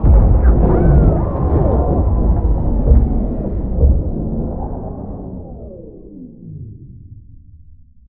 repairstop.ogg